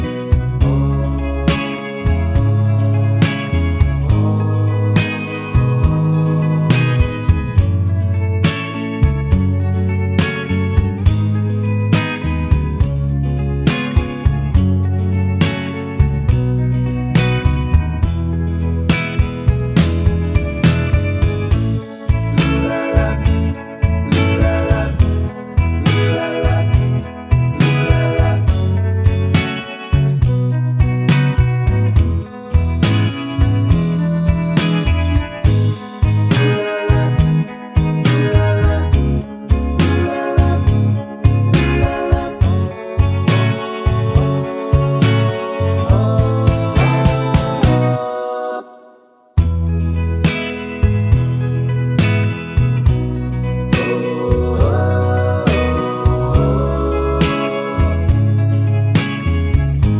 no Backing Vocals Glam Rock 4:05 Buy £1.50